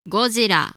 3. ^ Japanese: ゴジラ, Hepburn: Gojira, IPA: [ɡoꜜʑiɾa]
Ja-Godzilla.oga.mp3